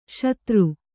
♪ śatru